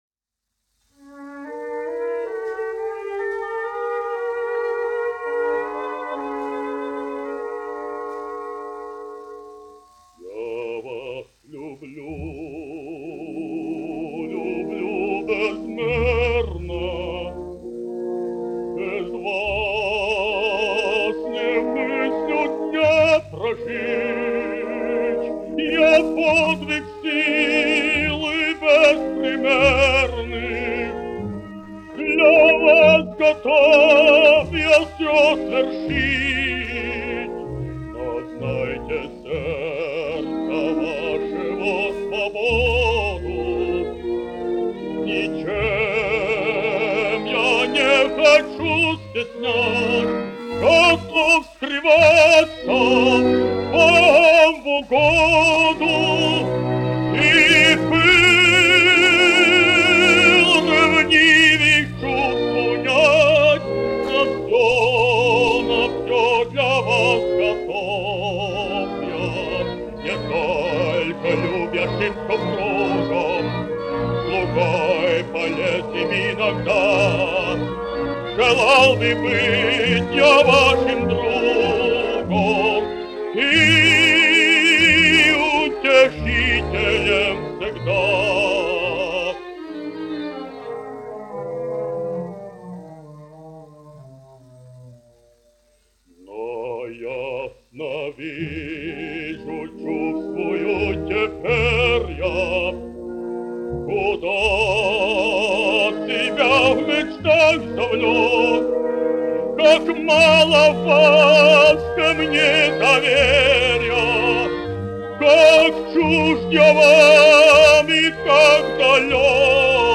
Kaktiņš, Ādolfs, 1885-1965, dziedātājs
1 skpl. : analogs, 78 apgr/min, mono ; 25 cm
Operas--Fragmenti
Latvijas vēsturiskie šellaka skaņuplašu ieraksti (Kolekcija)